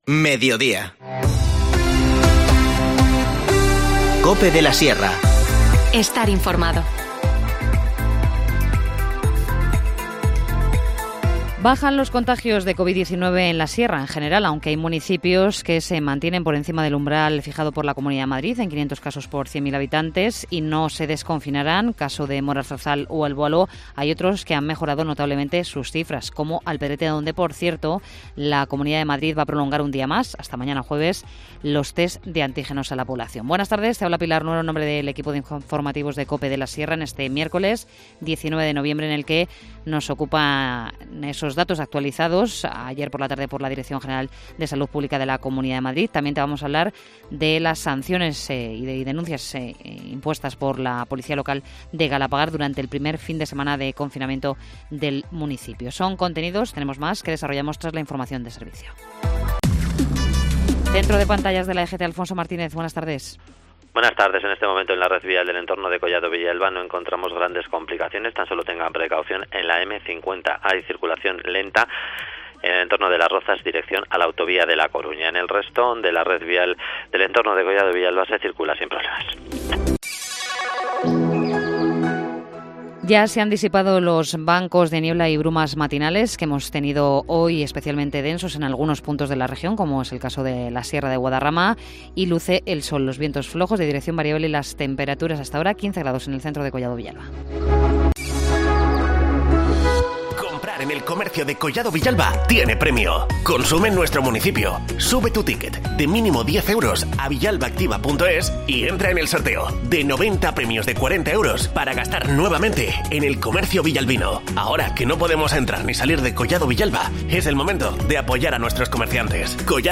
Informativo Mediodía 18 noviembre